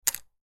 Mahjong-tile-match-or-placement-sound.mp3